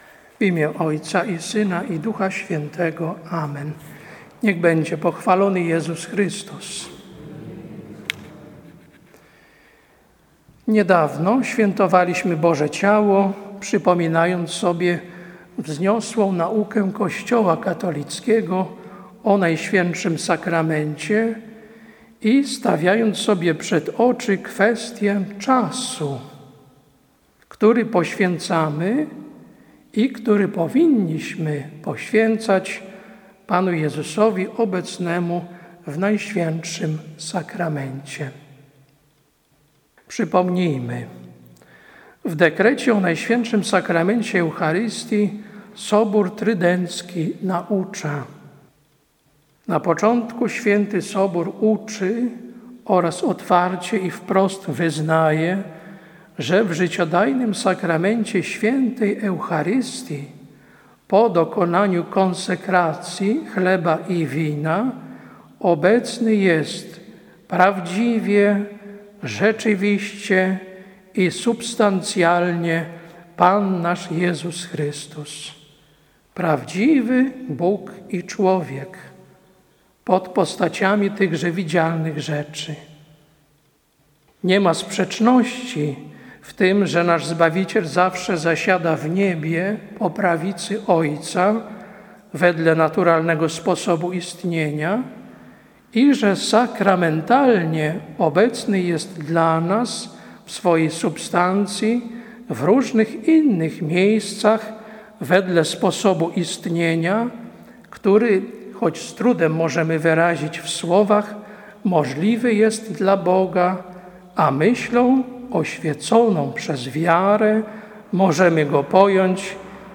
Kazanie na 4 Niedzielę po Zesłaniu Ducha Świętego, 28.06.2020 r.